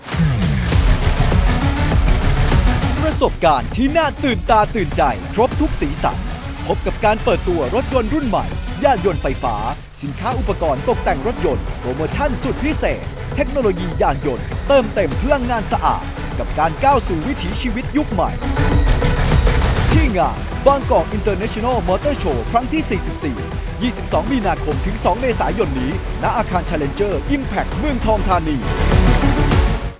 Radio Spot Motorshow44 30 วิ
Radio-Spot-motor-show-30-sec.wav